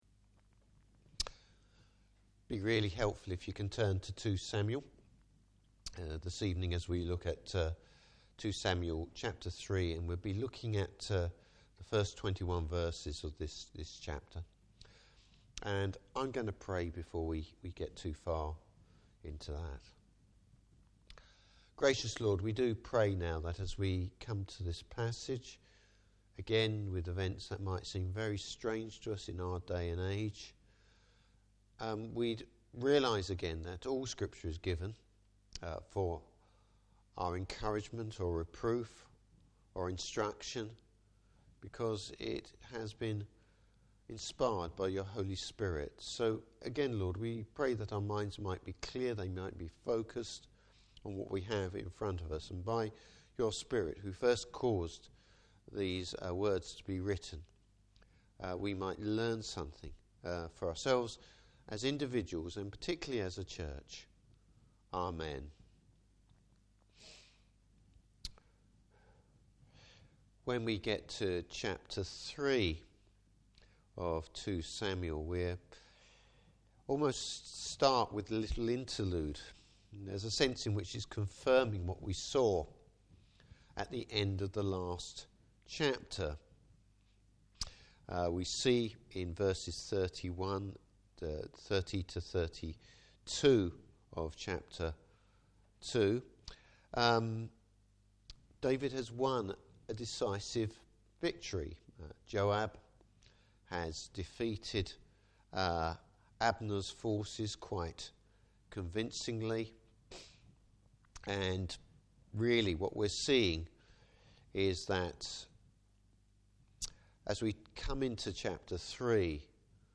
Service Type: Evening Service Abner’s ambition!